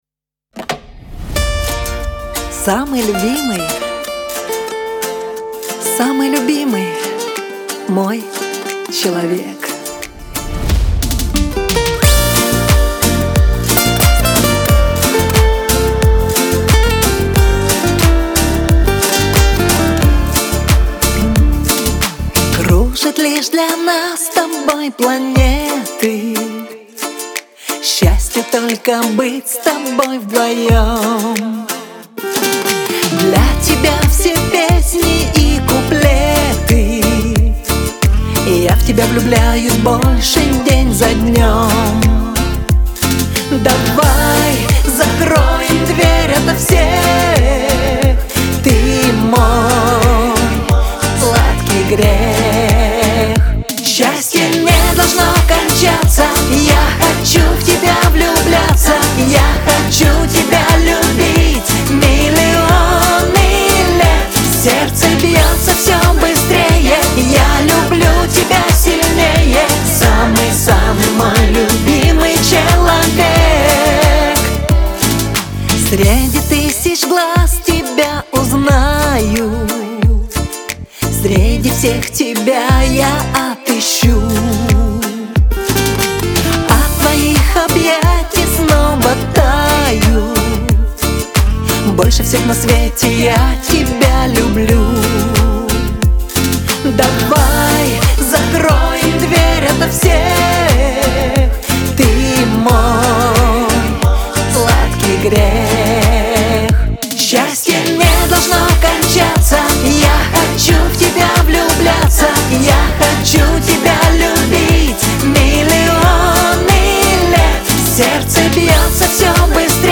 pop , Лирика
эстрада